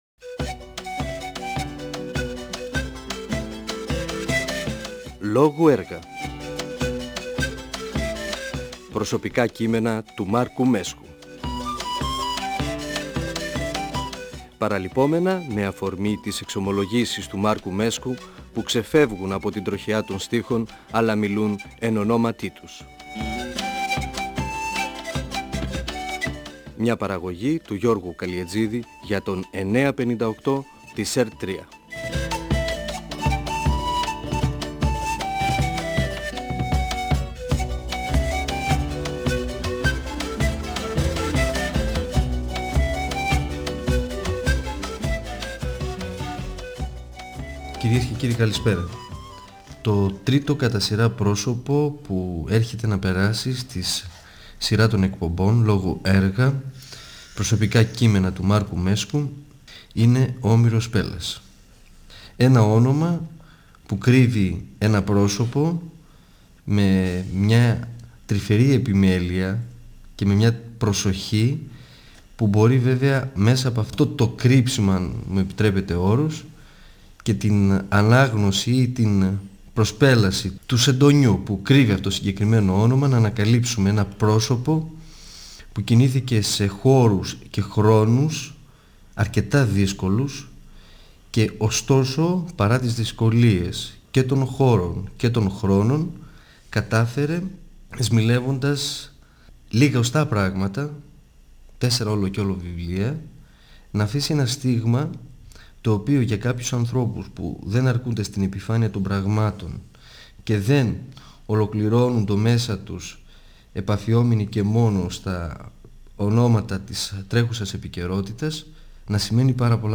Ο ποιητής και δοκιμιογράφος Μάρκος Μέσκος (1935-2019) μιλά (εκπομπή 1η) για τη ζωή και το έργο του πεζογράφου Όμηρου Πέλλα (ψευδώνυμο του Οδυσσέα Γιαννόπουλου).